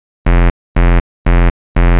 TSNRG2 Off Bass 017.wav